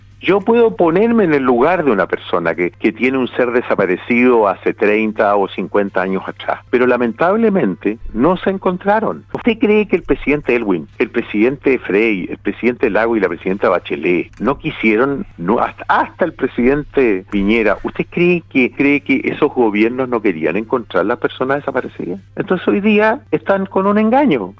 El senador UDI por la Región de Los Lagos, Iván Moreira, relativizó el anuncio realizado por el Presidente de la República, Gabriel Boric, respecto al Plan Nacional de Búsqueda de personas desaparecidas. En conversación con el programa «Primera Hora» de Radio Sago, el parlamentario gremialista y abiertamente partidario del periodo militar de Augusto Pinochet, dijo que este plan es un engaño, porque «si no aparecieron en 50 años, no van a venir hoy día (sic) a aparecer».